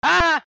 AAAH!.mp3